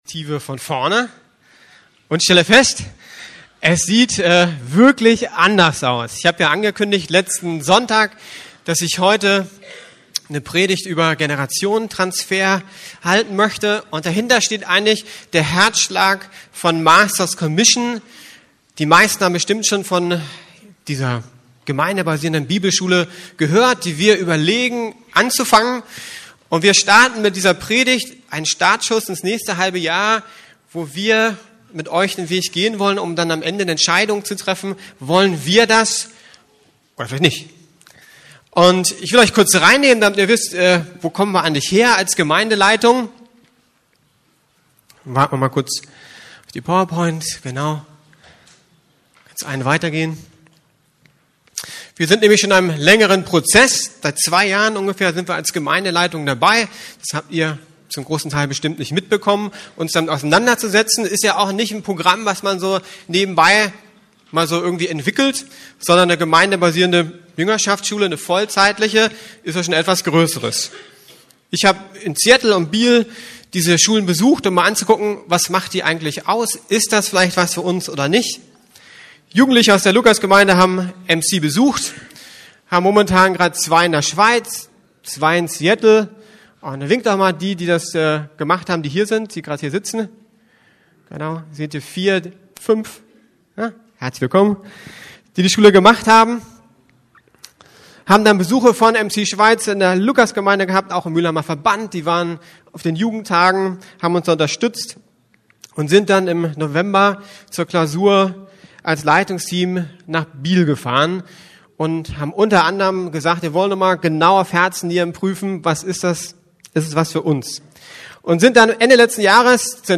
The Next Generation ~ Predigten der LUKAS GEMEINDE Podcast